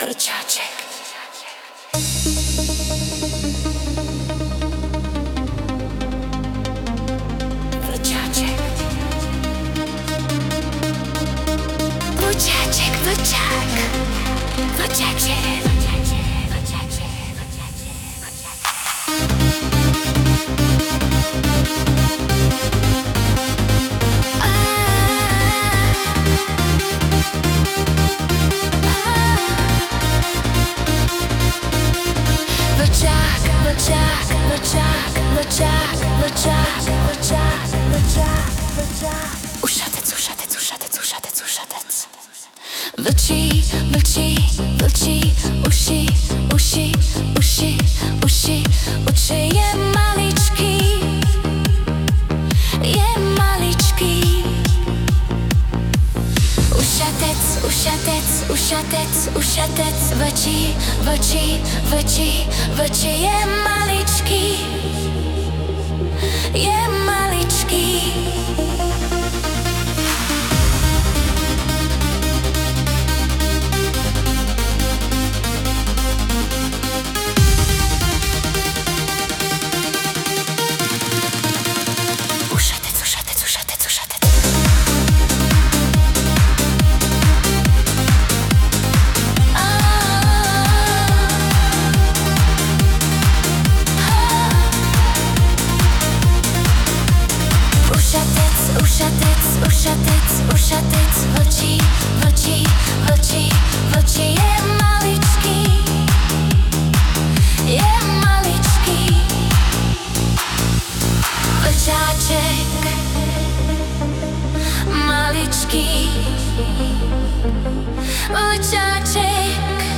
Tato písnička, která je mimochodem naprosto o ničem vznikla tak, že jsem potřeboval přestat vnímat bolest po dvou vytržených zubech. Natřískal jsem do umělé inteligence pár slov a dal jí za úkol vytvořit takovou taneční pecku, kterou nepůjde pustit z hlavy.